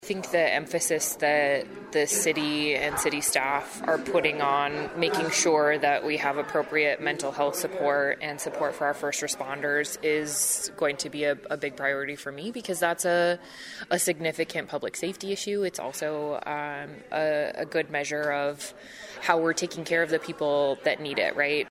THE SIOUX CITY COUNCIL HELD THEIR ANNUAL MEETING AT CITY HALL WITH IOWA LAWMAKERSS FROM THE METRO AREA ON FRIDAY, AND SHARED A LIST OF CONCERNS AND GOALS FOR THE UPCOMING STATE LEGISLATIVE SESSION.
CAITLIN DREY IS STARTING HER FIRST TERM AS A STATE SENATOR AND LISTENED TO THE CITY’S CONCERNS: